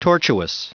Prononciation du mot tortuous en anglais (fichier audio)
Prononciation du mot : tortuous